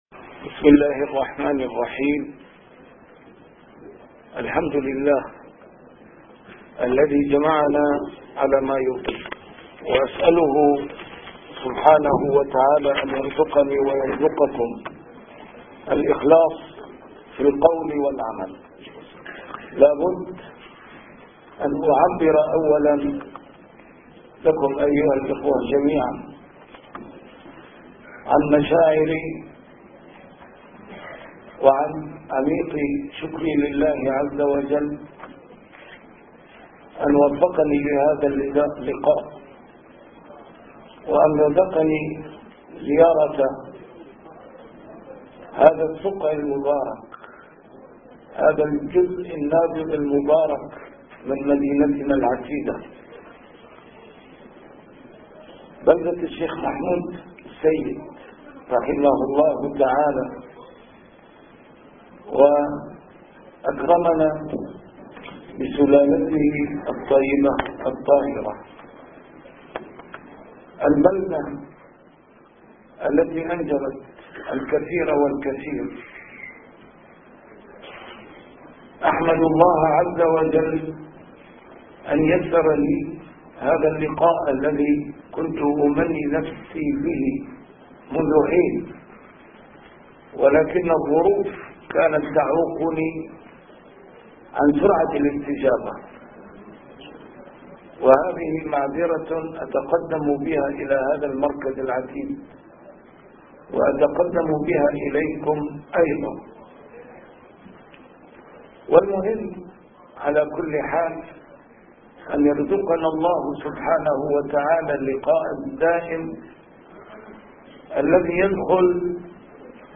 A MARTYR SCHOLAR: IMAM MUHAMMAD SAEED RAMADAN AL-BOUTI - الدروس العلمية - محاضرات متفرقة في مناسبات مختلفة - محاضرة للعلامة الشهيد في مدينة دوما